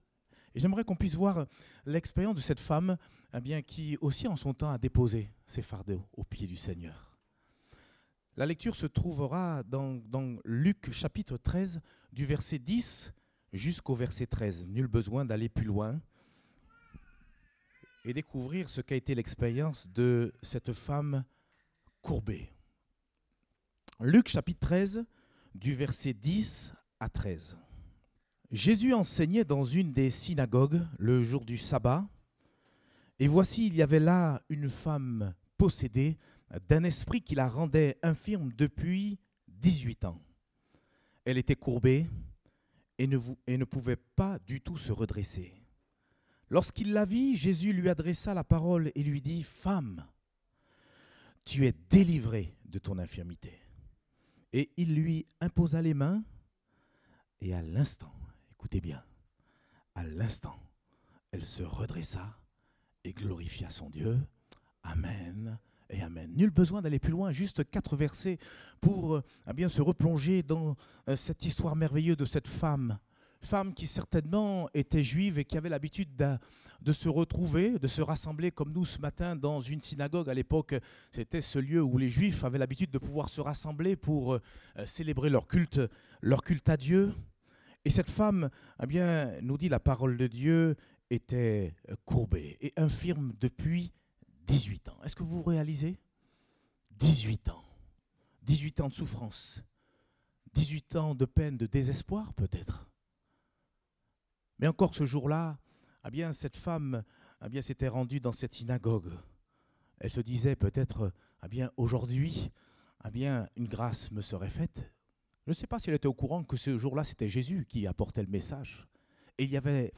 Date : 20 mai 2018 (Culte Dominical)